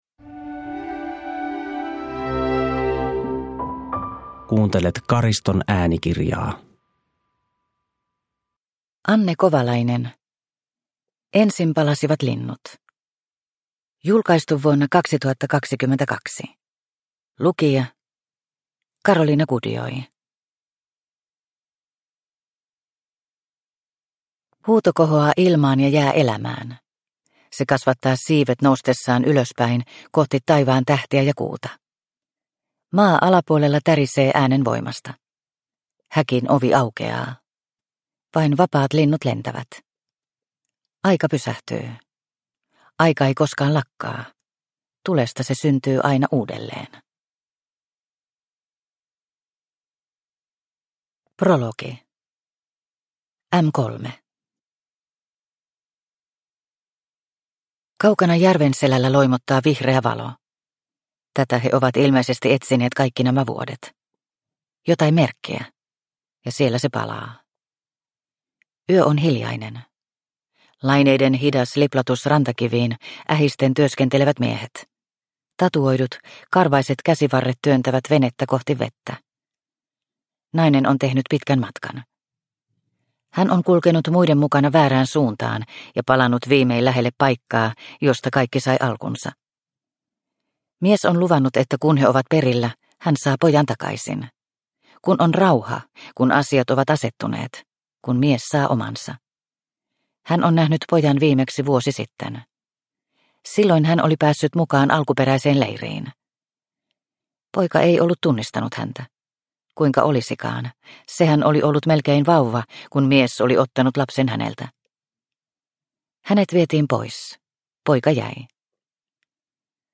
Ensin palasivat linnut (ljudbok) av Anne Kovalainen